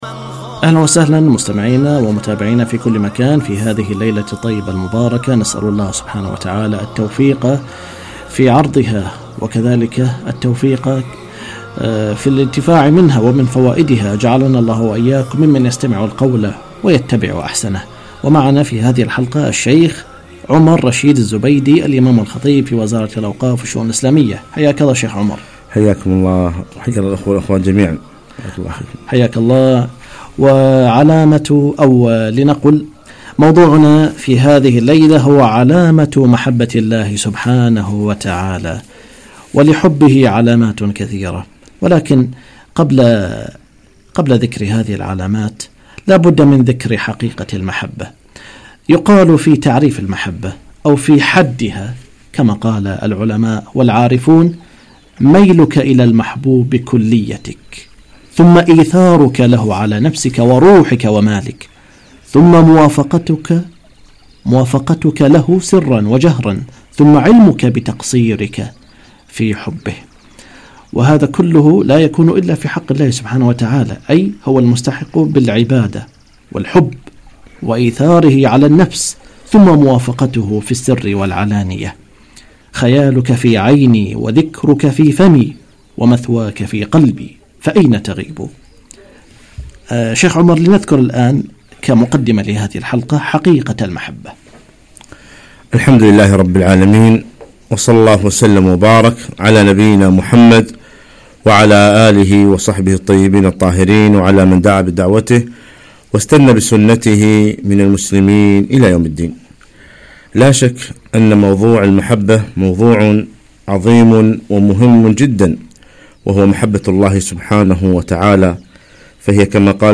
علامة محبة الله - لقاء إذاعي